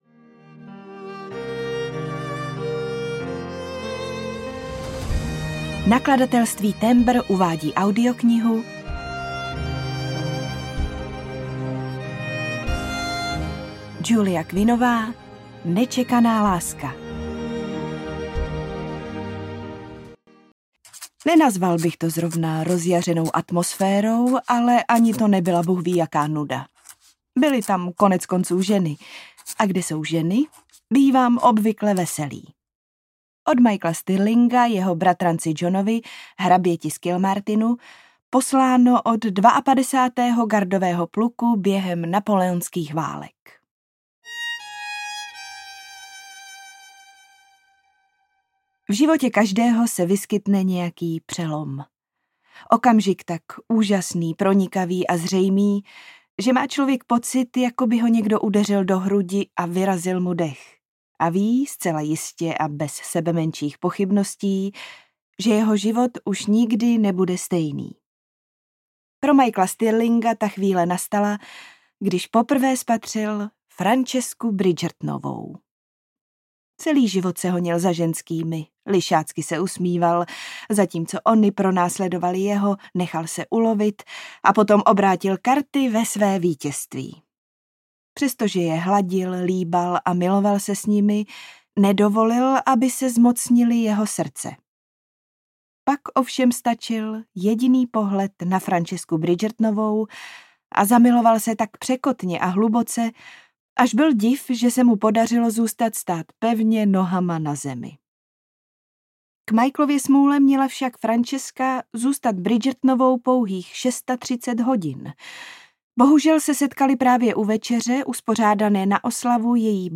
Bridgertonovi: Nečekaná láska audiokniha
Ukázka z knihy
bridgertonovi-necekana-laska-audiokniha